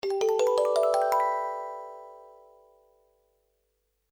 Cortinilla musical del cuento
melodía
sintonía
Sonidos: Música